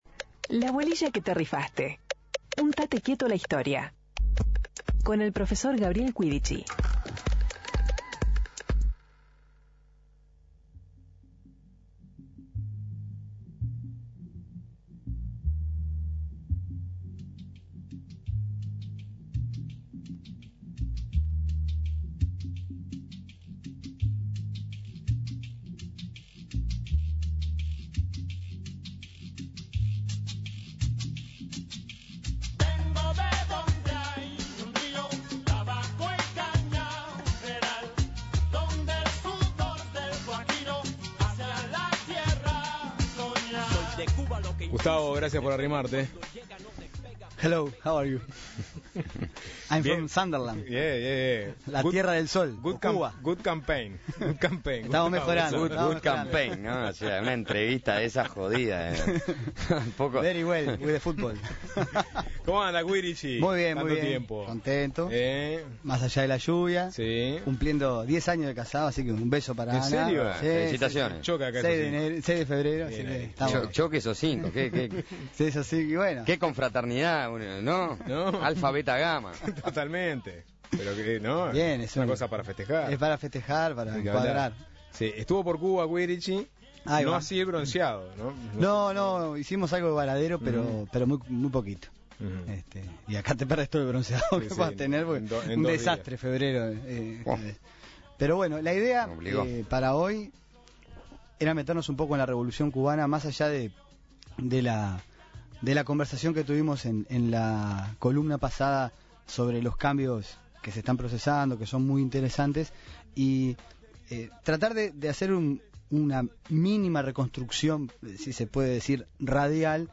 Además de discursos particulares del propio Fidel Castro